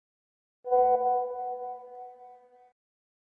جلوه های صوتی
دانلود صدای واتساپ 1 از ساعد نیوز با لینک مستقیم و کیفیت بالا
برچسب: دانلود آهنگ های افکت صوتی اشیاء دانلود آلبوم صدای پیام واتساپ از افکت صوتی اشیاء